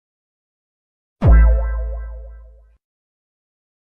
RIZZ-Sound-Effect.mp3